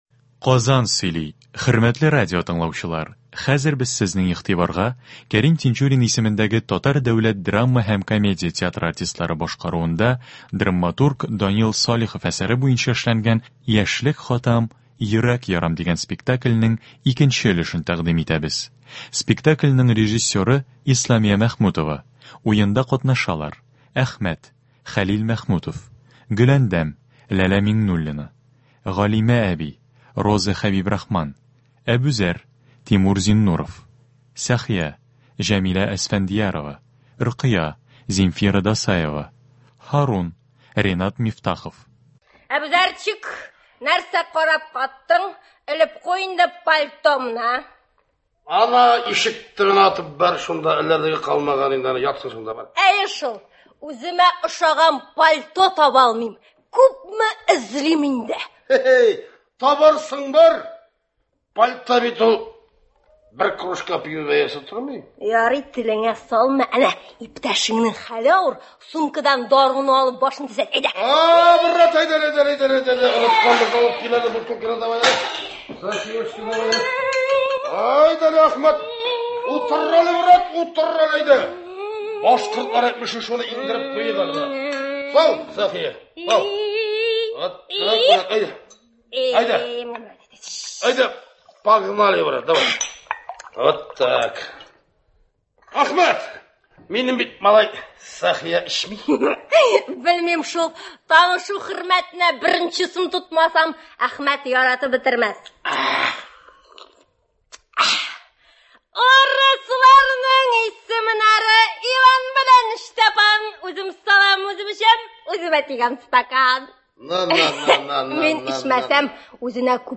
К.Тинчурин ис. ТДДК театры спектакленең радиоварианты.